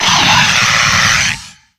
Audio / SE / Cries / TOUCANNON.ogg